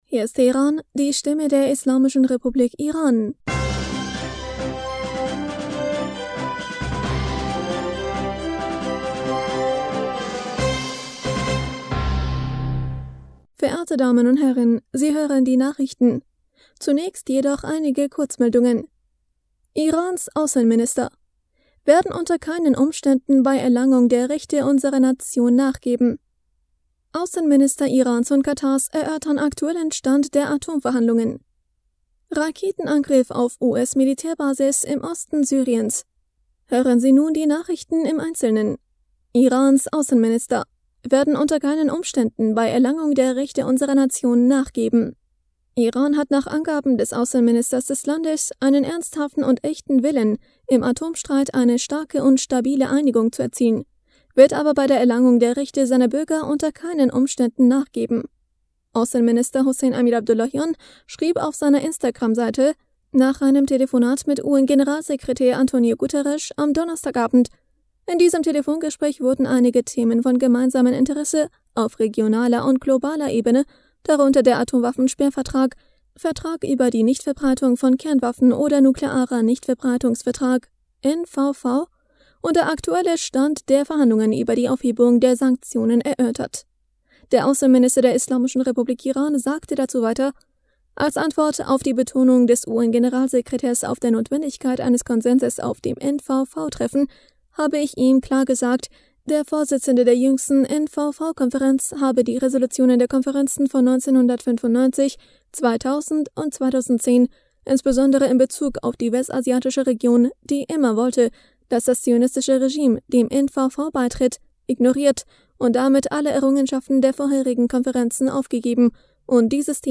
Nachrichten vom 26. August 2022